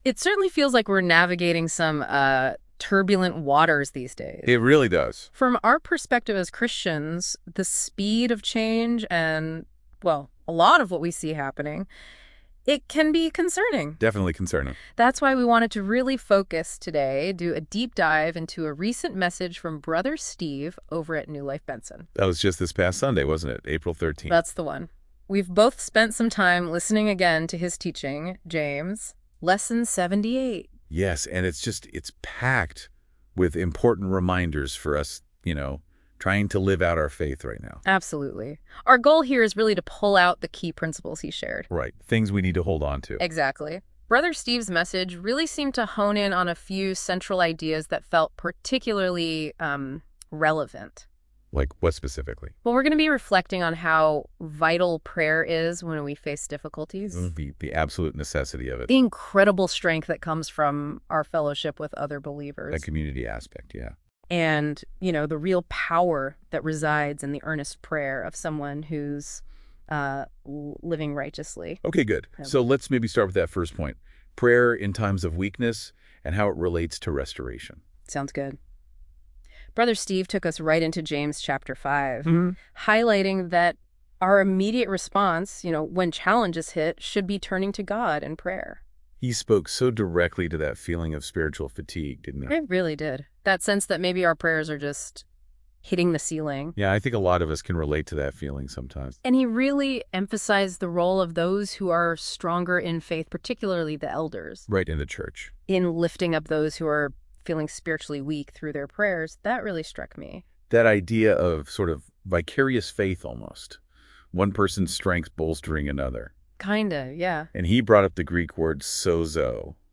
Sermons | NEW LIFE FAMILY WORSHIP CENTER